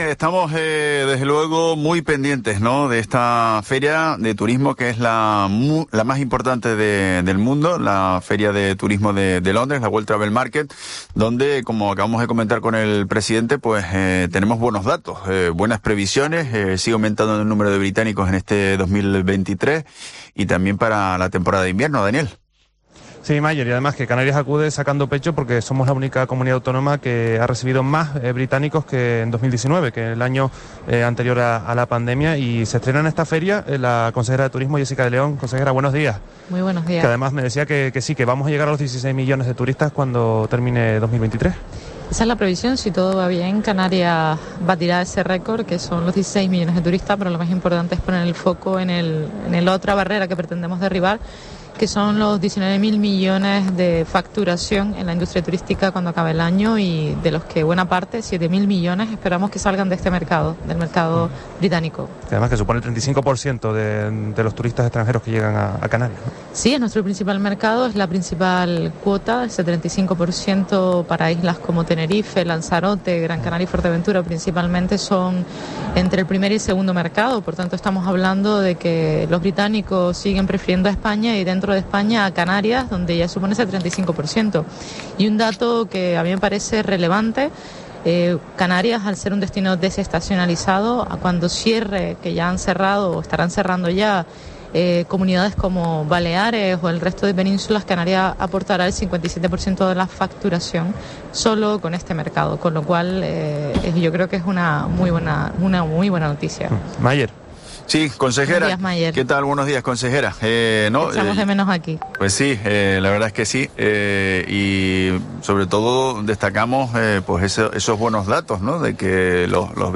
La consejera regional de Turismo Jessica de León, celebró hoy en Herrera en COPE Canarias que este año 2023, “llegaremos a los 16 millones de turistas y pretendemos llegar a 19.000 millones de facturación”, destacando que el británico, “es nuestro principal mercado, con una cuota del 75%, en islas como Tenerife, Fuerteventura, Gran Canaria o Lanzarote”.